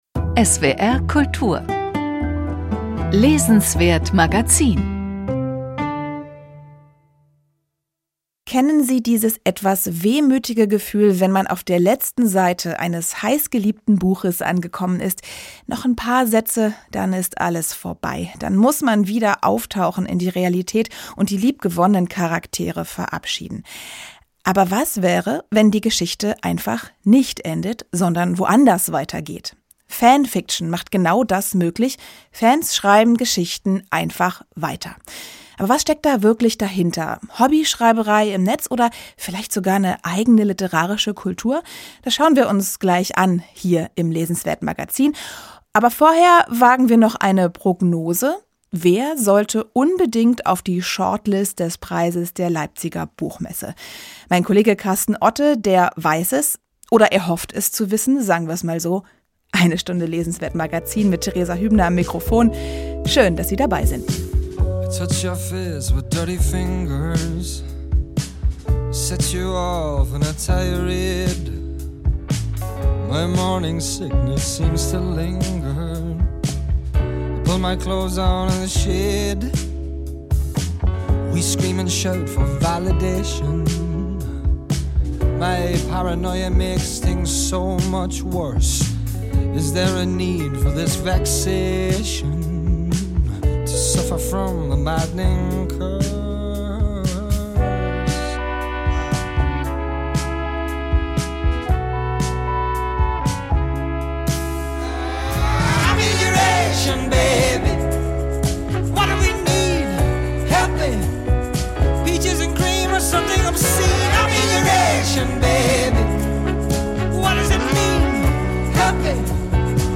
Kapitalismus auf zwei Rädern bei Tomer Gardi, eine Prognose zu Favoriten für die Shortlist des Preises der Leipziger Buchmesse und ein Gespräch über Fan Fiction